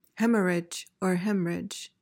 PRONUNCIATION:
(HEM-uhr-ij, HEM-rij)